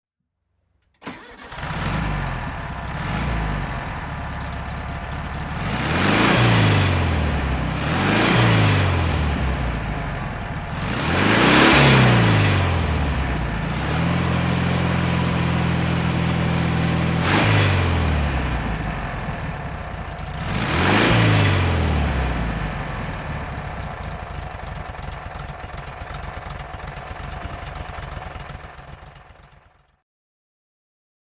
VW Karmann Ghia (1968) - Starten und Leerlauf
VW_Karmann-Ghia_1968.mp3